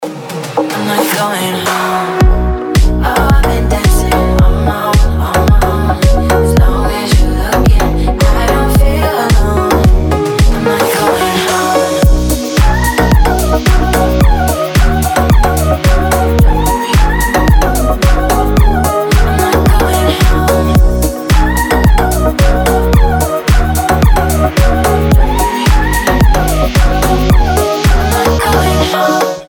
• Качество: 256, Stereo
dance
club
nu disco
house
колокольчики